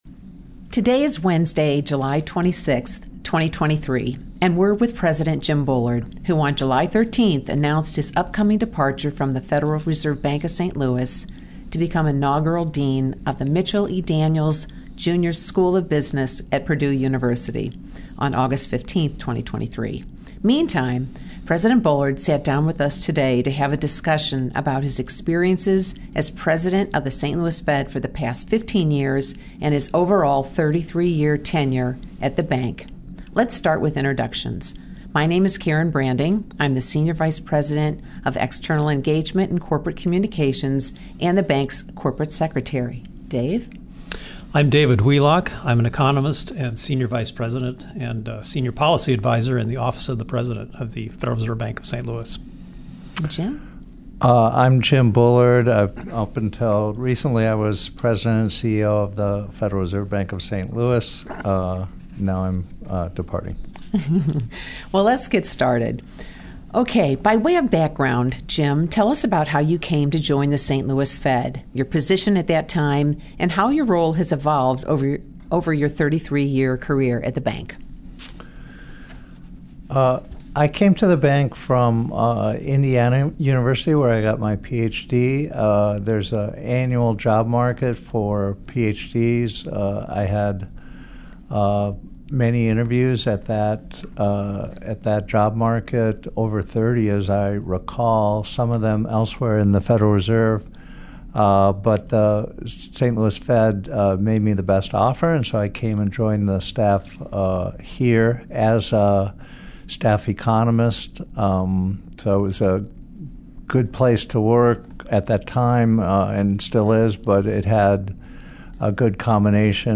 Interview with James Bullard